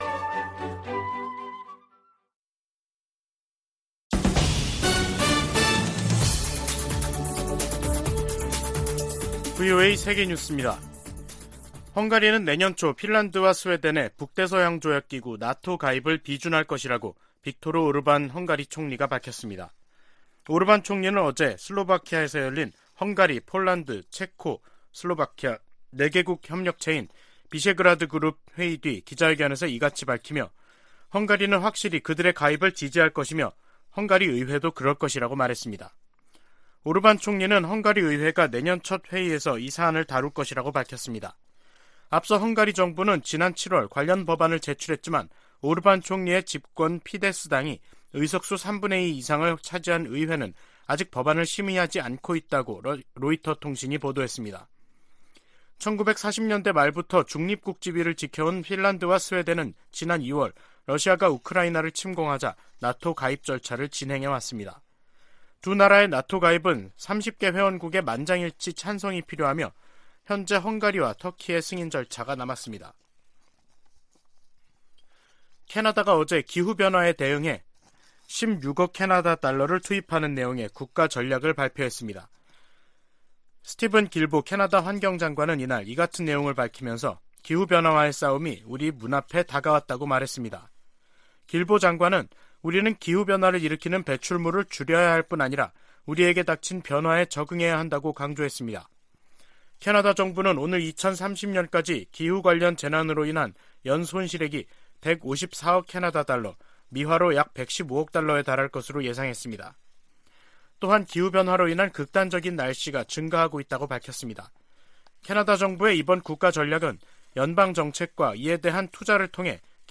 VOA 한국어 간판 뉴스 프로그램 '뉴스 투데이', 2022년 11월 25일 2부 방송입니다. 미국 의회에서 북한의 도발을 방조하는 ‘세컨더리 제재’등으로 중국에 책임을 물려야 한다는 요구가 거세지고 있습니다. 미국 고위 관리들이 최근 잇따라 북한 문제와 관련해 중국 역할론과 책임론을 거론하며 중국의 협력 의지를 시험하고 있다는 전문가 분석이 제기됐습니다.